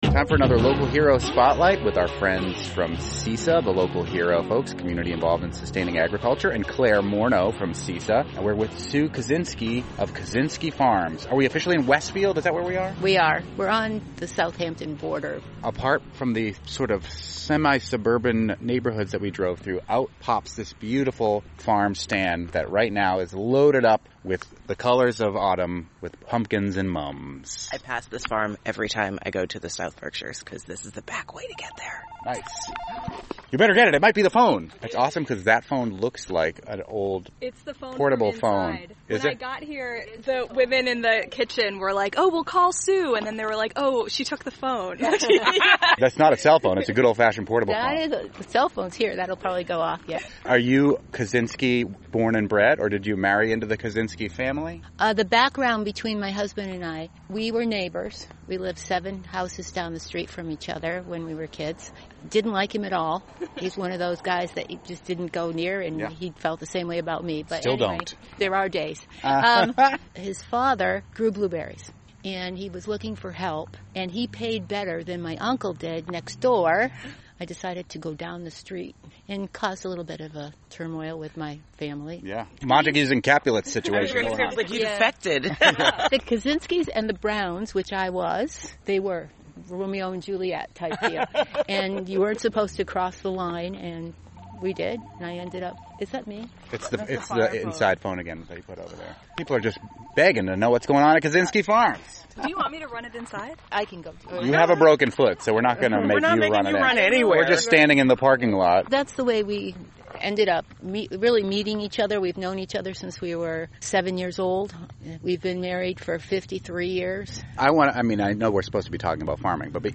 NEPM "Fabulous 413" interviews